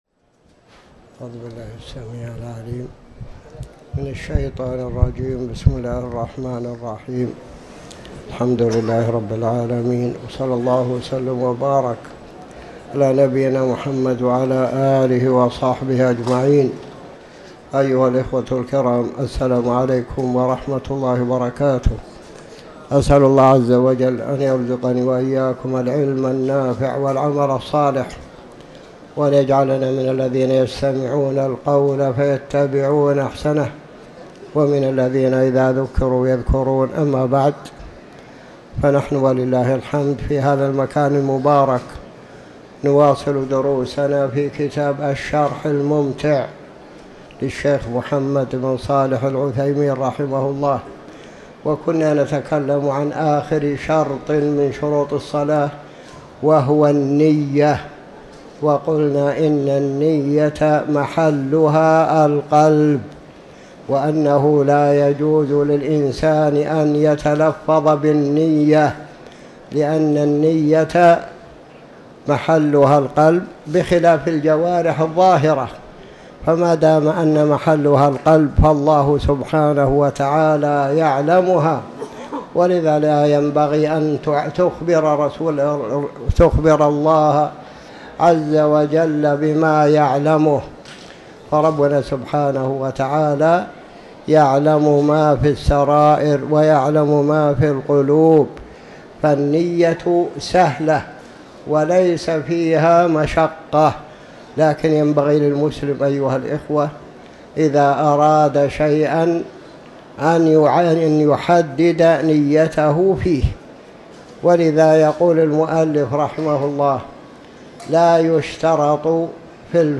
تاريخ النشر ٢٠ شوال ١٤٤٠ هـ المكان: المسجد الحرام الشيخ